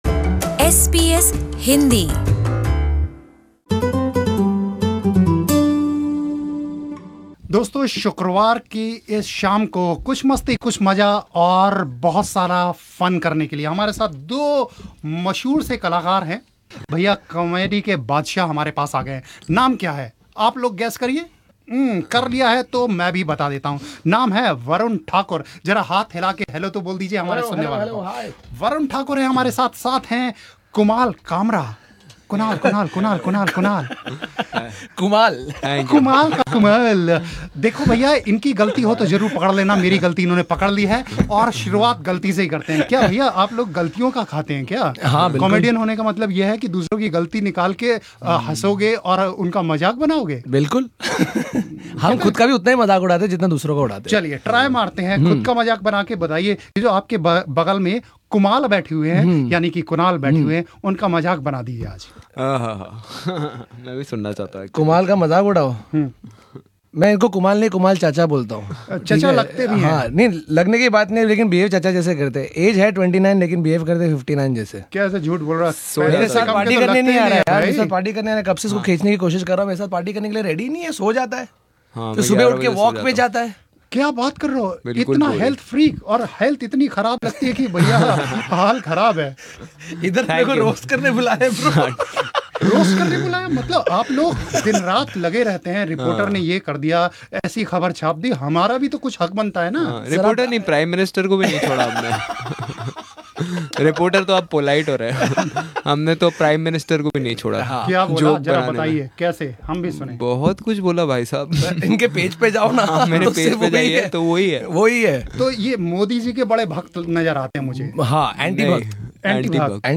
Here is your dose of Friday Funny Fix with famous Indian Stand Up comedians Varun Thakur and Kunal Kamra.